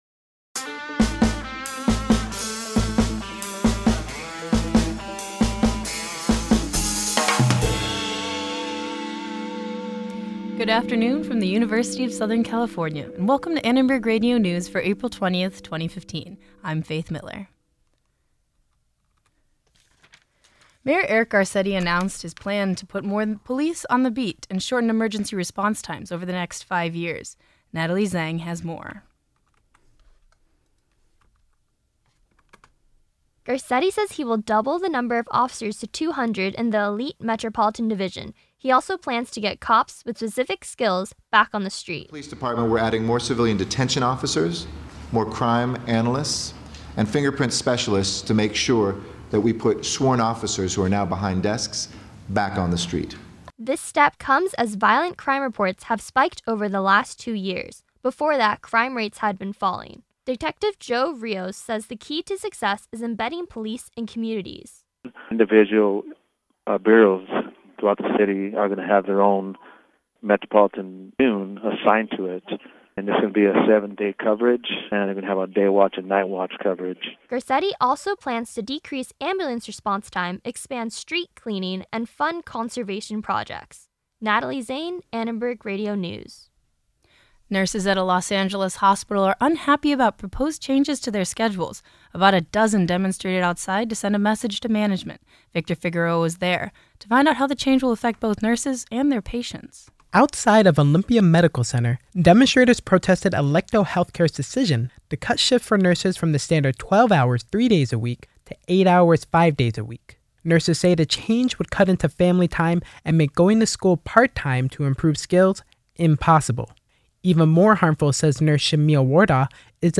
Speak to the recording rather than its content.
ARN Live Show - April 20, 2015 | USC Annenberg Radio News